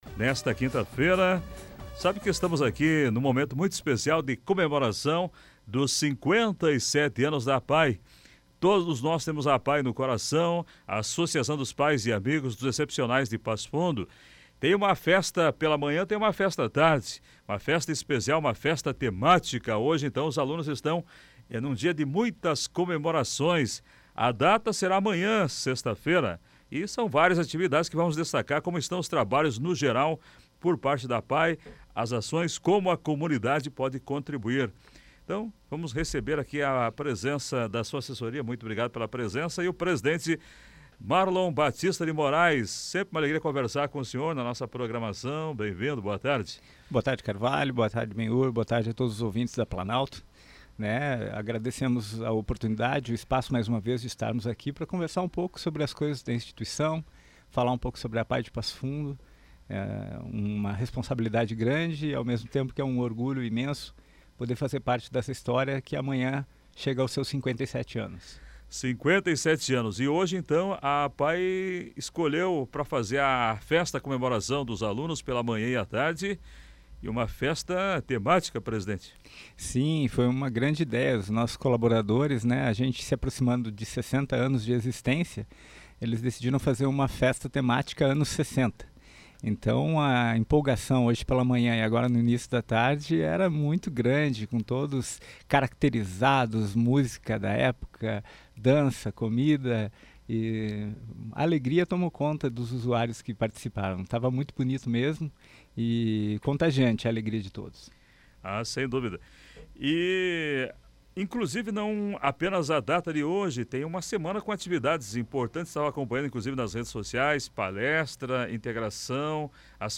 ENTREVISTA-APAE.mp3